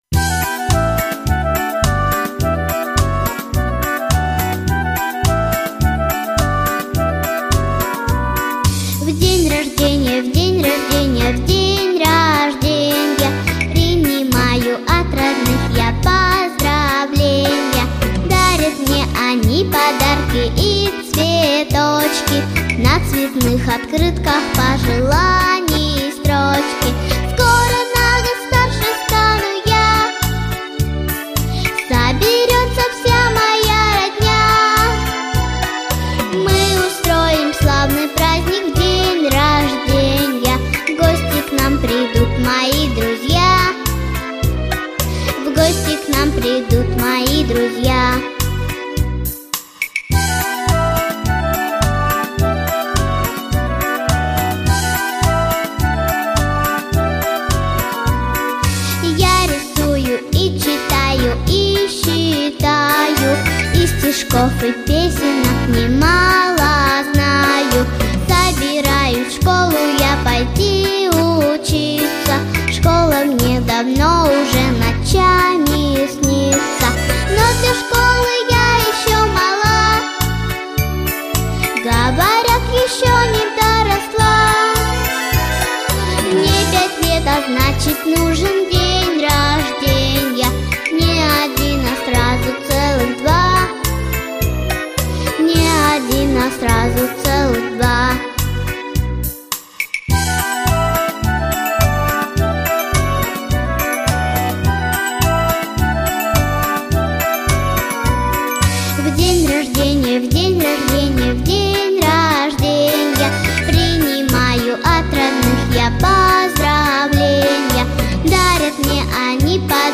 песня.